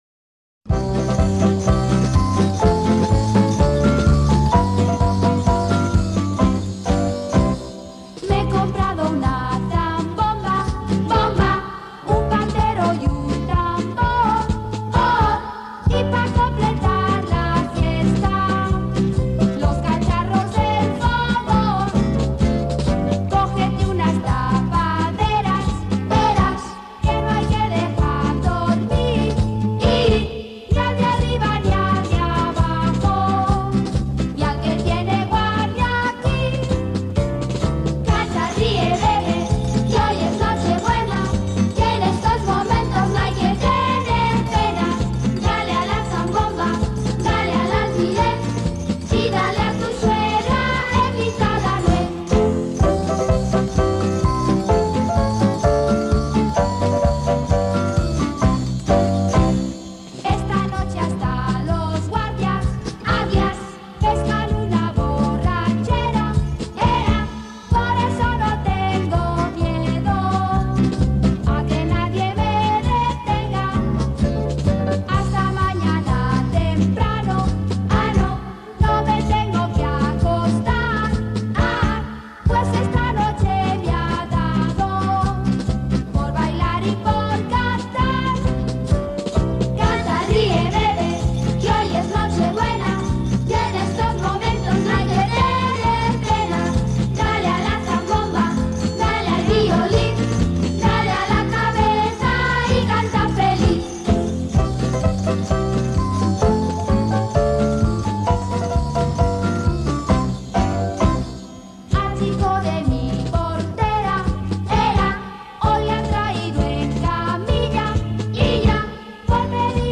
Villancicos subversivos y violentos
Resulta que el célebre e inocente ‘Canta, ríe y bebe’ se ha radicalizado con una malignidad final bastante evidente, en este caso audible, ubicada en la frase final de la popular melodía navideña.